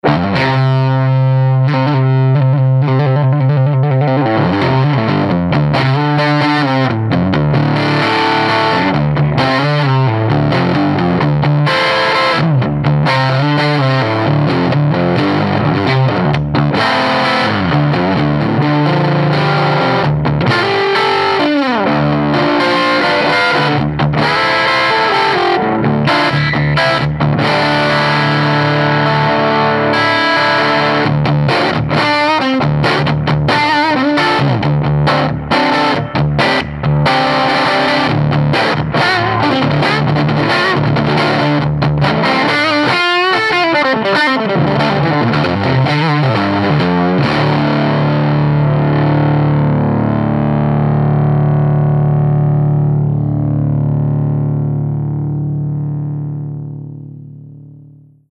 5W Class A - Single-Ended - 6V6 or 6L6 - Tube Rectified ~ ALL NEW Triode REVERB ~ 14lbs
Shadows • Tele • High Gain   :58